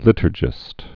(lĭtər-jĭst)